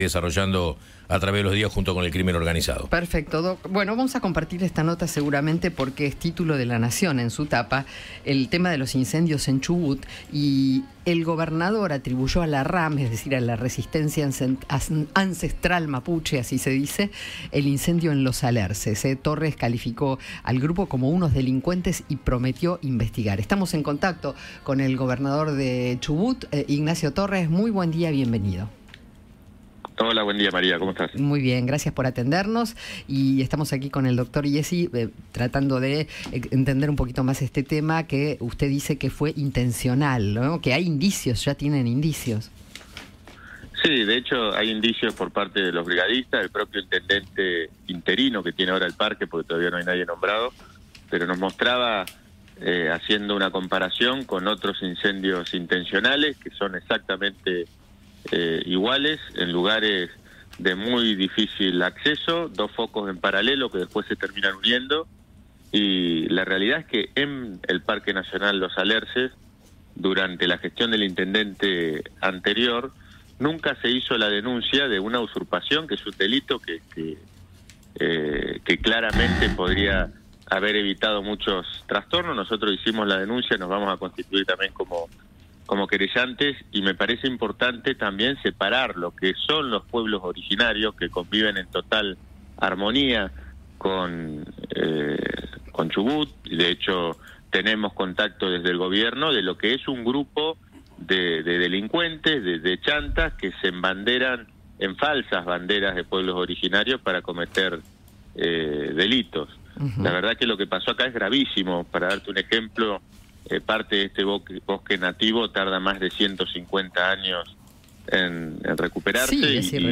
Ignacio Torres habló con el equipo de Alguien Tiene que Decirlo sobre los incendios en el Parque Nacional Los Alerces y calificó a los integrantes de la Resistencia Ancestral Mapuche (RAM) de “delincuentes”.